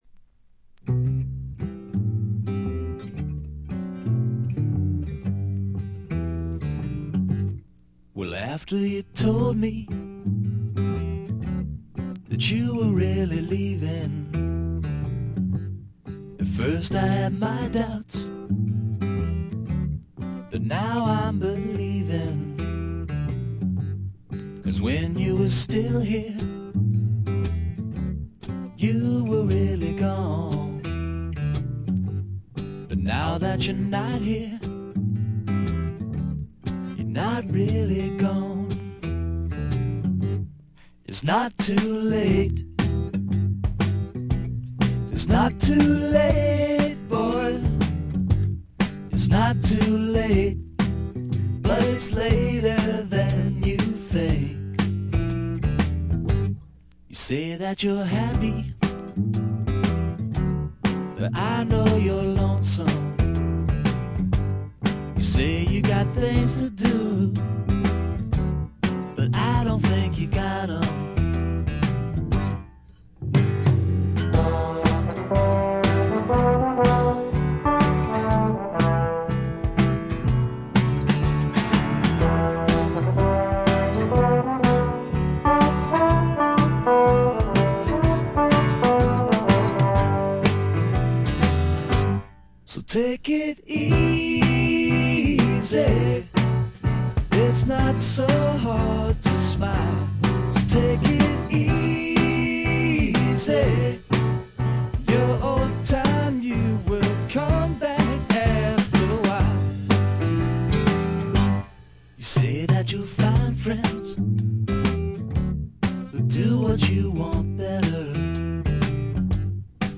細かなチリプチや散発的なプツ音は少し出ますが良好に鑑賞できます。
内容はいかにもボストンらしく、フォーク、ドリーミー・ポップ、グッタイム・ミュージック等を下地にした実にごきげんなもの。
試聴曲は現品からの取り込み音源です。